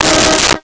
Car Horn 02
Car Horn 02.wav